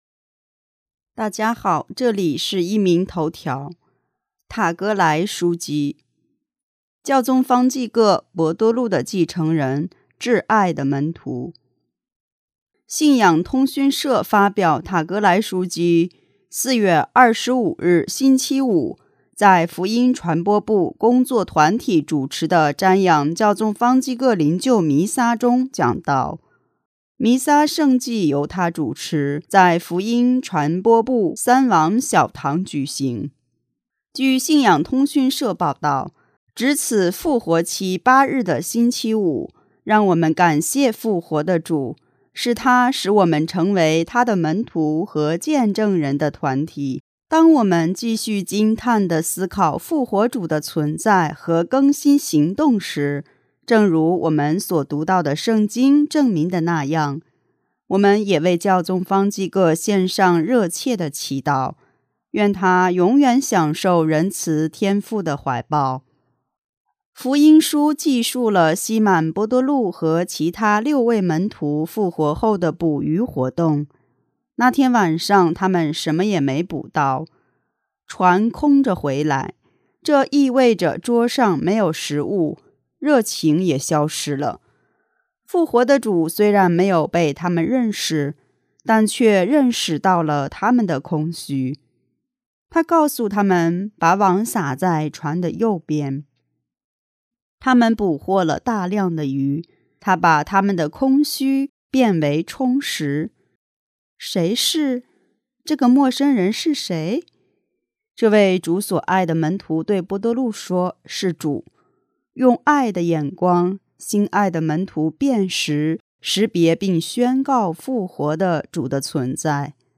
弥撒圣祭由他主持，在福音传教部三王小堂举行。